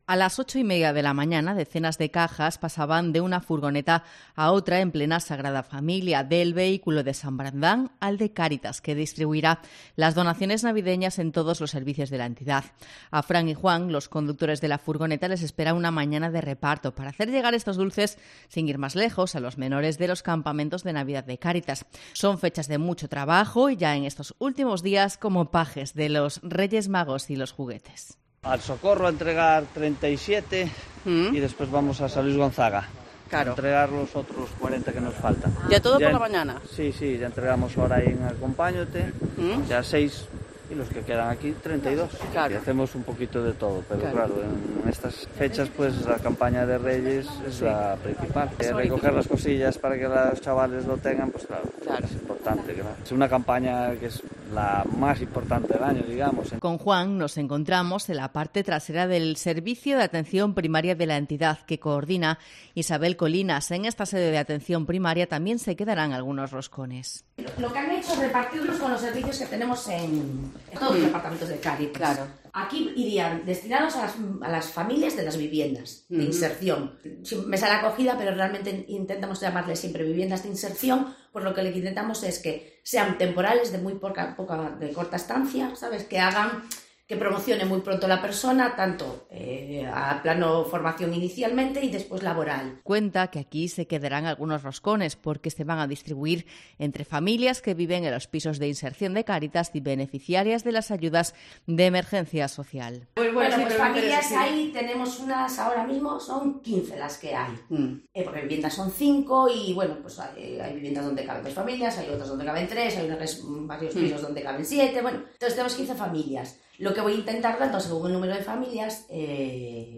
Crónica de la entrega de los primeros roscones solidarios de Sanbrandán a Cáritas